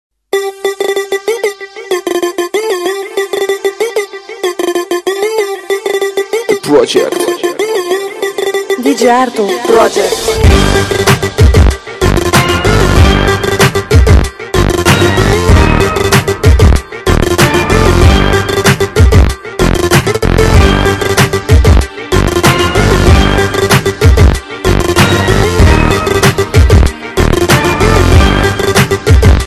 • Качество: 80, Stereo
громкие
басы
electro house